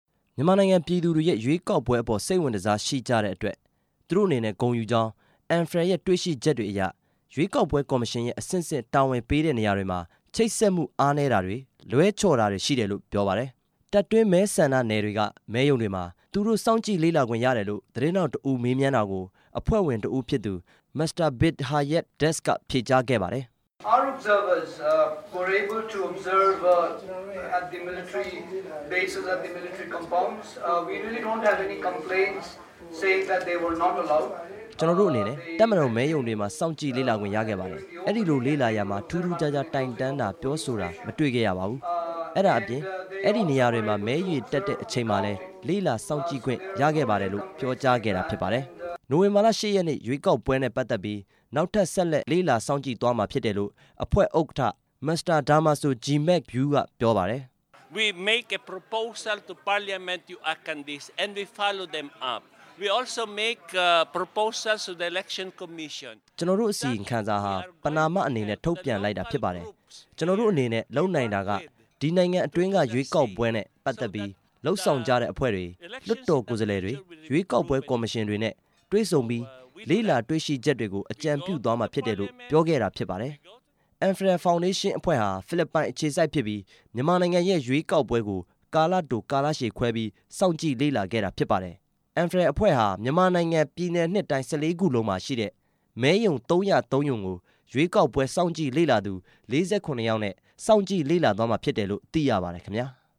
ရွေးကောက်ပွဲကို စောင့်ကြည့်လေ့လာခဲ့တဲ့ (ANFREL) အဖွဲ့ သတင်းစာရှင်းလင်း